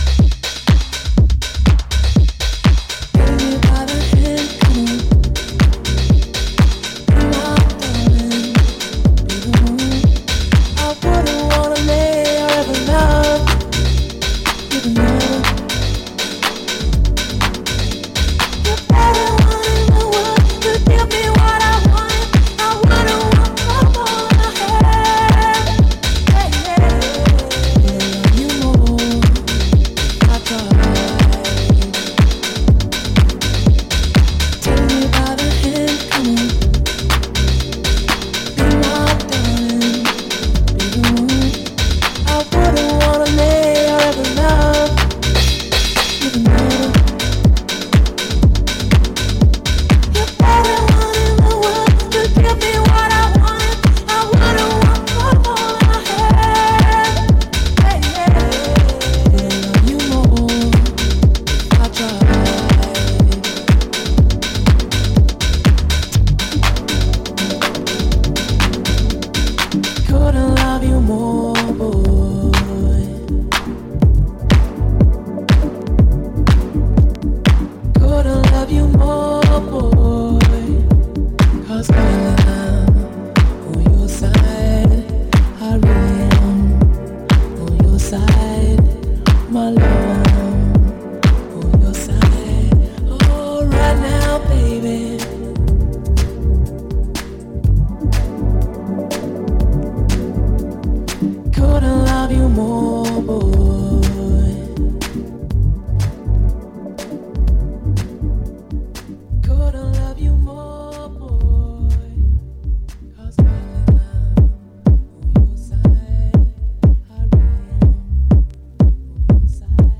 Edit house tracks